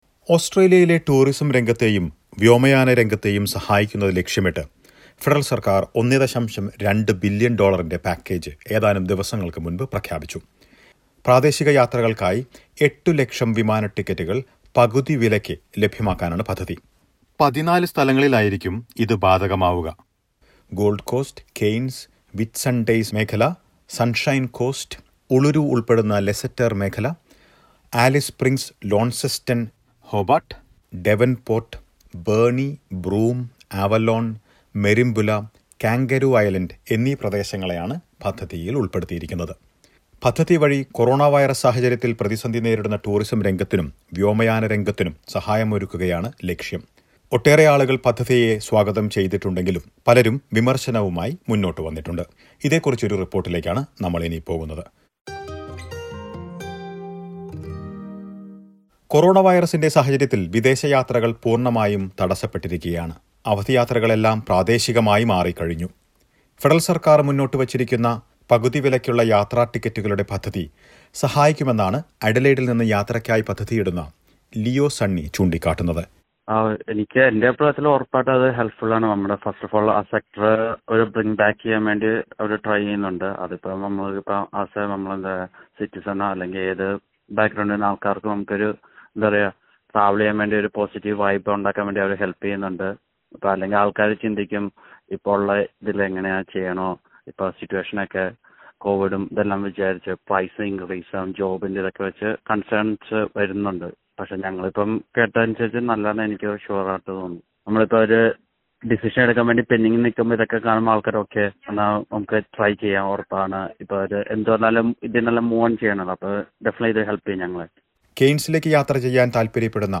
Australian Government has announced that it will subsidise domestic ticket fares to help the struggling tourism and airline sector. A new package will see 800,000 tickets given away for half the cost during the April-May season. Listen to some reactions from potential travellers and people in the tourism sector about the package.